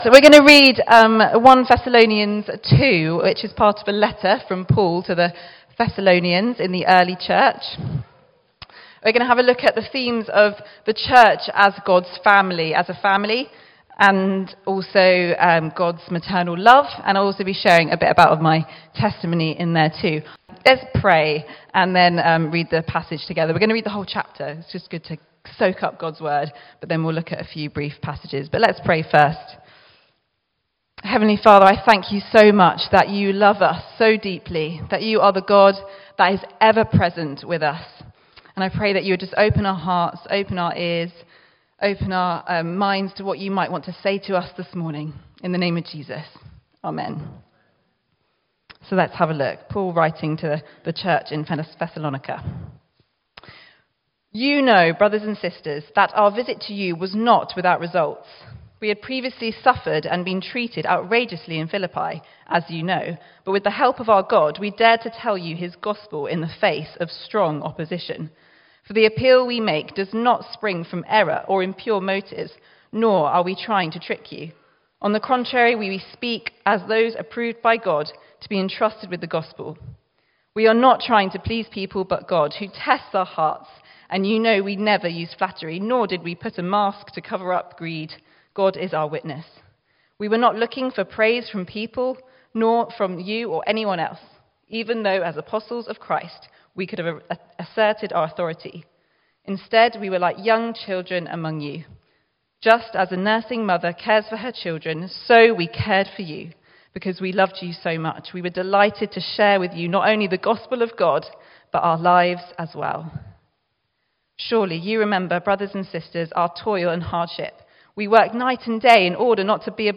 Mothering Sunday (1 Thessalonians 2) (Part recording)